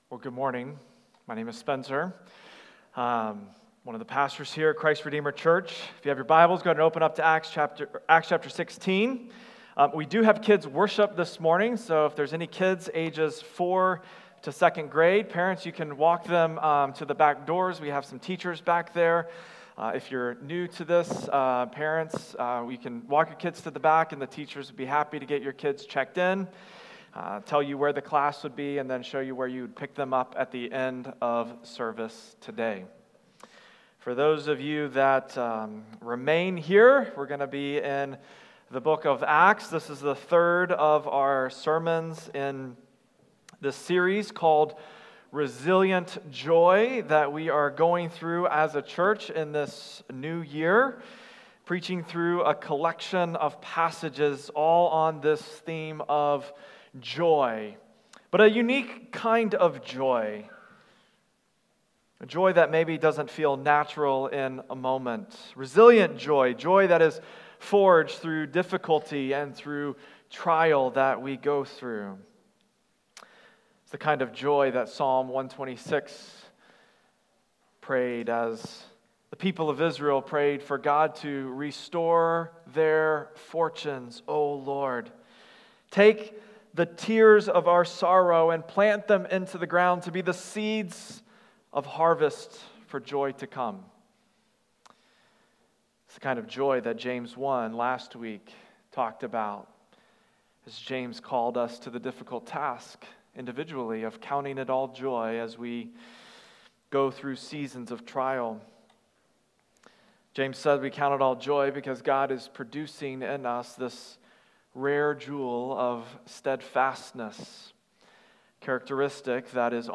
Weekly Sunday Sermons from Christ Redeemer Church in Cottage Grove, MN